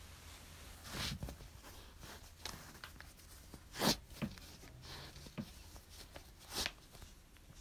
Hint: je trekt het aan en dan voer je de handeling uit.
Veters strikken?